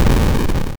collapse_block_fall.ogg